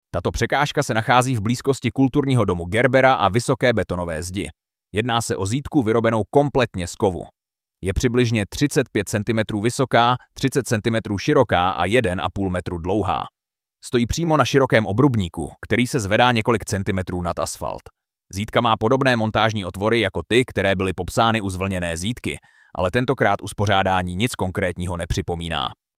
AUDIOPOPIS ZÍDKA